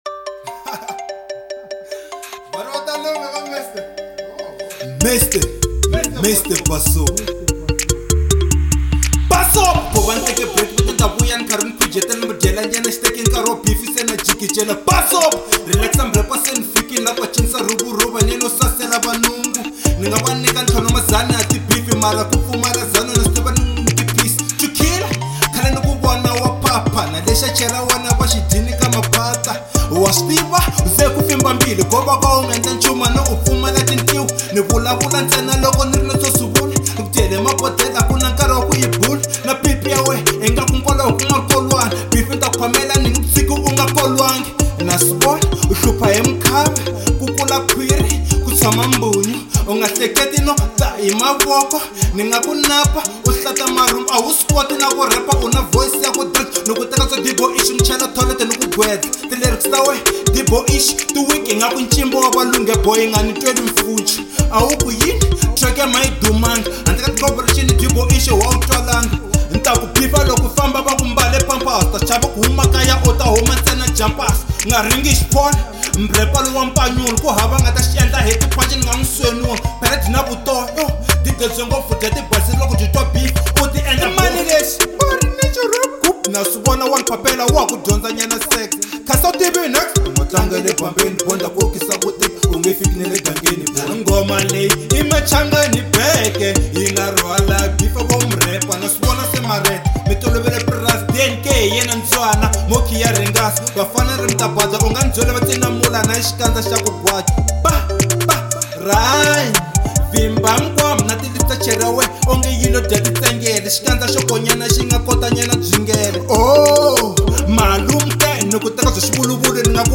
03:33 Genre : Hip Hop Size